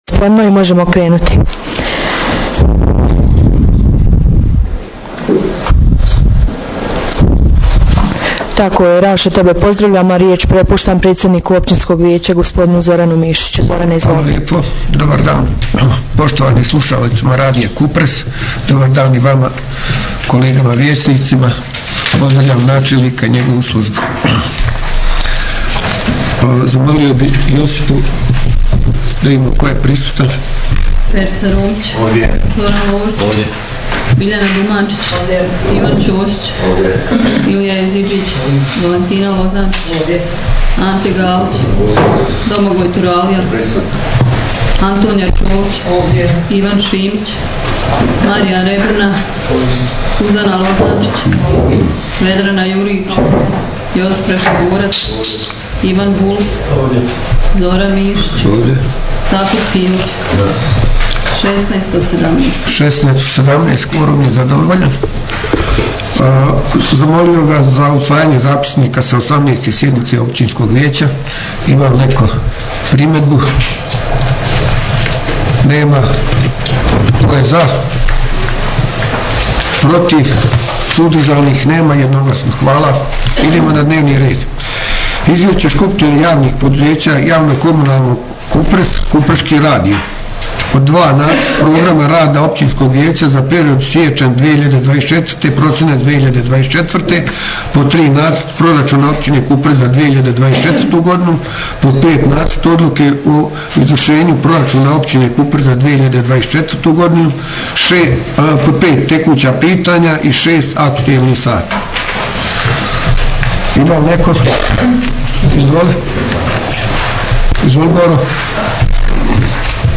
Jučer 04. prosinca 2023. s početkom u 15 sati održana je 19. sjednica OV Kupres na kojoj je bilo prisutno 16 od 17 vijećnika.